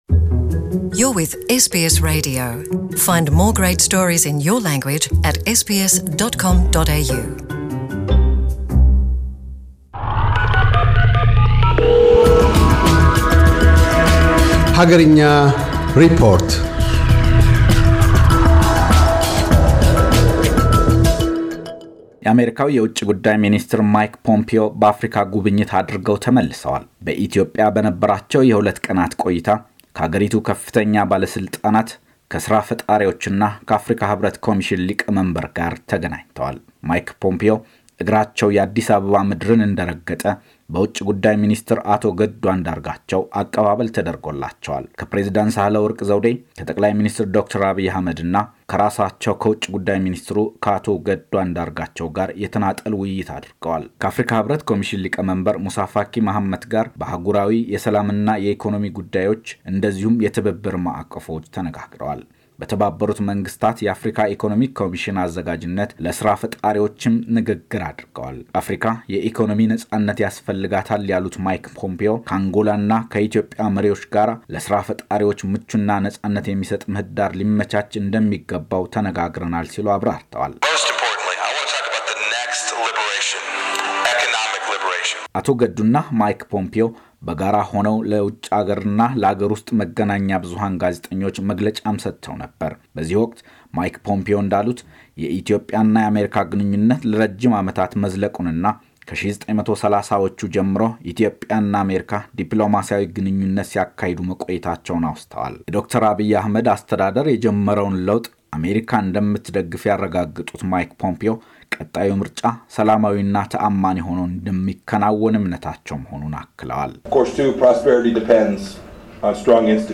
አገርኛ ሪፖርት - የዩናይትድ ስቴትስ የውጭ ጉዳይ ሚኒስትር ማይክ ፖምፔዮን አጠቃላይ የኢትዮጵያ ጉብኝት ሪፖርት ይዘግባል።